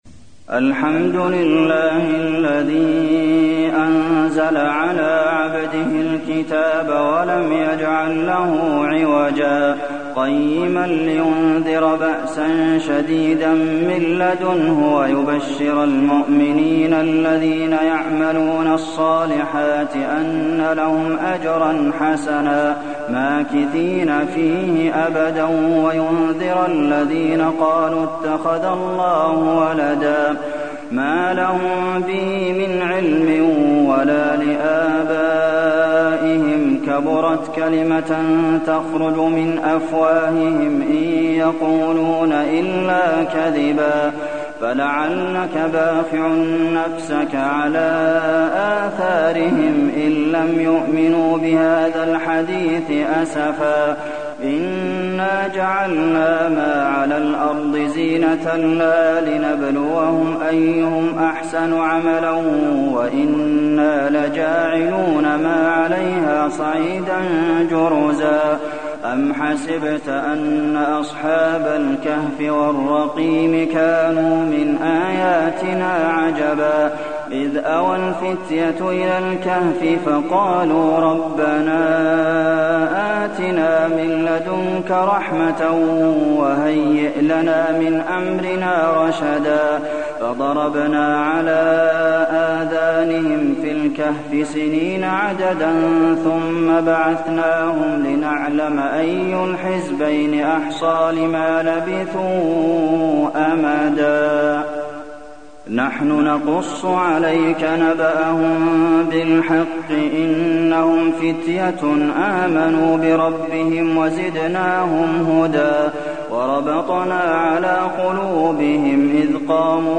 المكان: المسجد النبوي الكهف